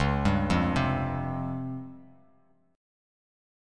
C#M7b5 Chord